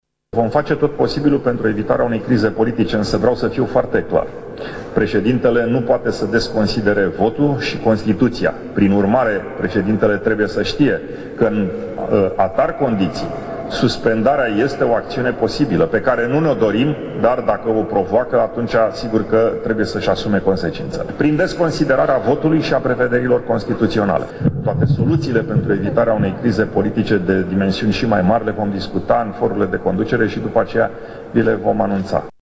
Presedintele Senatului a precizat că PSD si ALDE se vor consulta si va fi „o decizie comună” în privința numirii unui alt premier, iar suspendarea președintelui nu este de dorit, dar este posibilă: